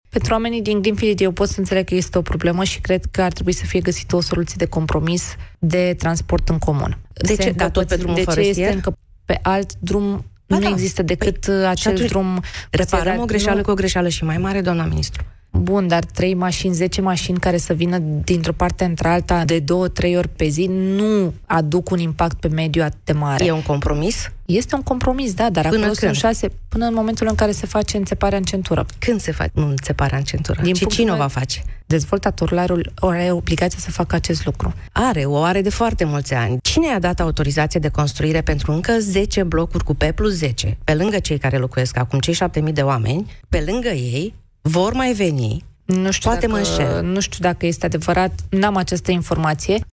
Invitată la Misiunea Verde